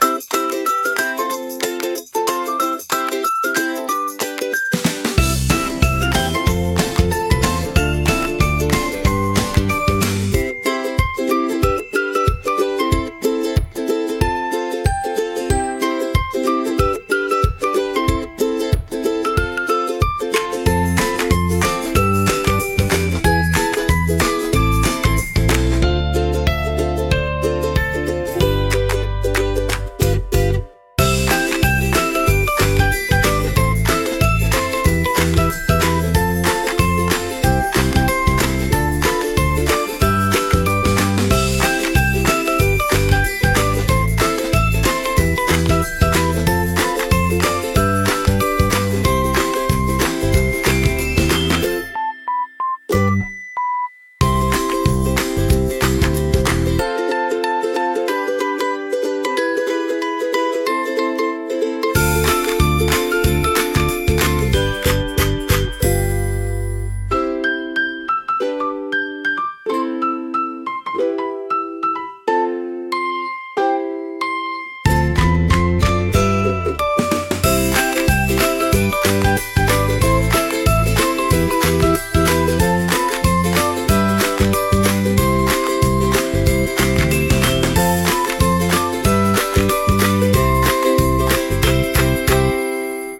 Fun Kids Melody